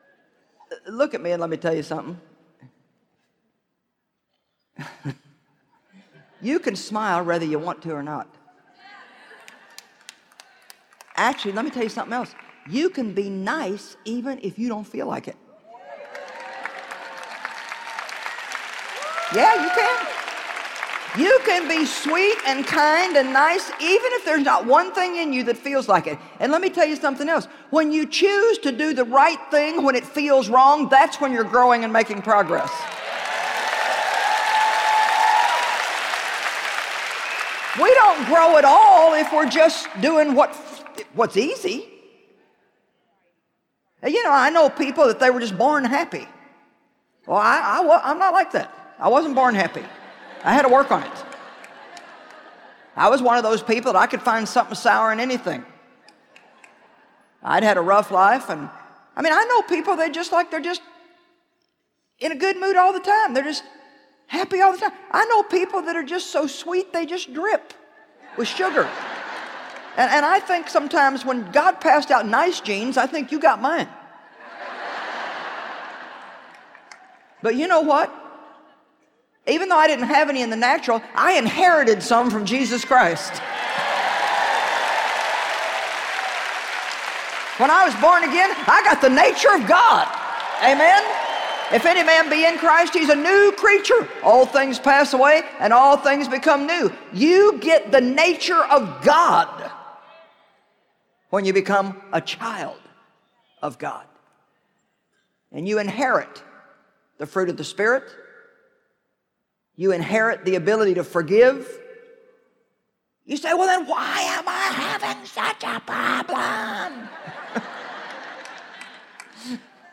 Winning Life’s Battles Audiobook
Narrator